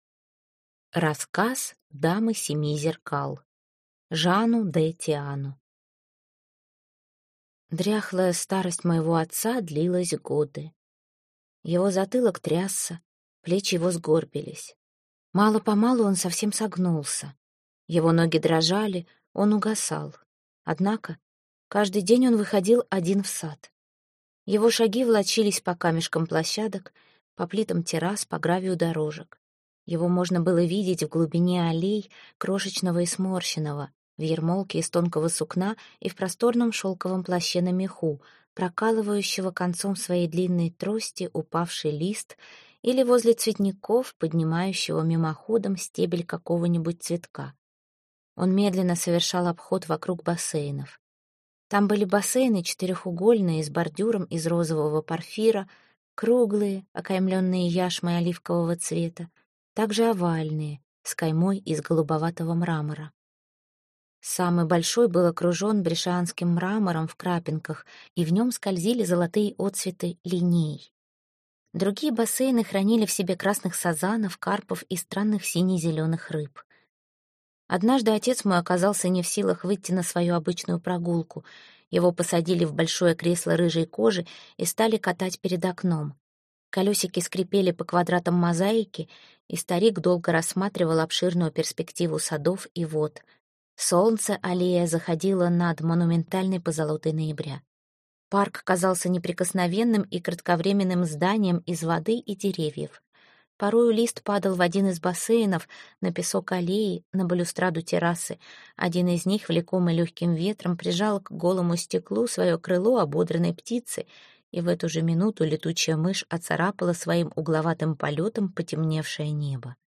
Аудиокнига Сказки для самого себя | Библиотека аудиокниг